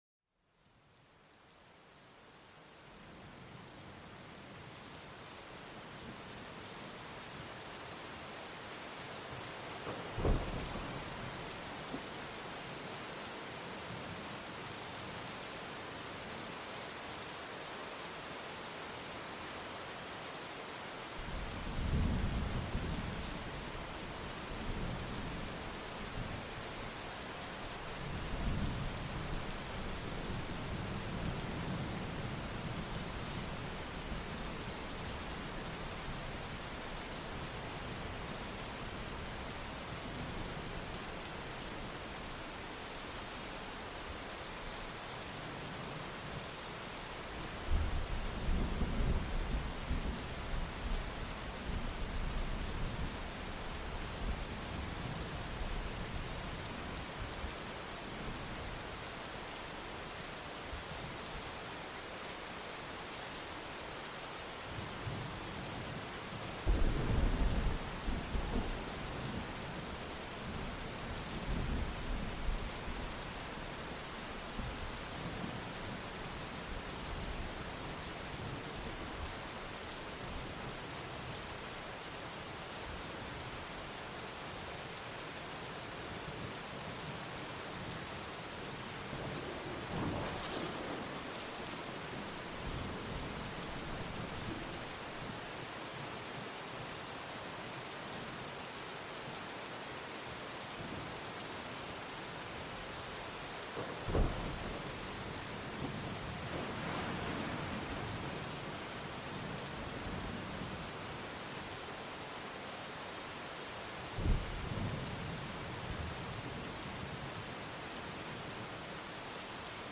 À travers les sons de l'orage, du vent, et des vagues, nous créons un espace de relaxation profonde et de méditation, aidant à apaiser l'esprit et à inviter au sommeil.
Immergez-vous dans l'intensité d'un orage, où chaque éclair illumine le ciel nocturne et chaque coup de tonnerre résonne comme un tambour céleste.